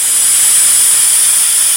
cooker.mp3